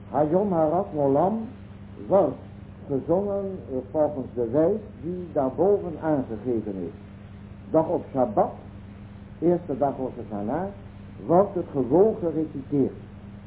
recited (not sung) on Shabbat.
During Musaph the שברים-תרועה tone is blown as two separate tones, with a short break in between.